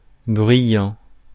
Ääntäminen
Ääntäminen France: IPA: /bʁɥi.jɑ̃/ Haettu sana löytyi näillä lähdekielillä: ranska Käännös Ääninäyte Adjektiivit 1. noisy US 2. blustery 3. boisterous 4. raucous 5. loud US Suku: m .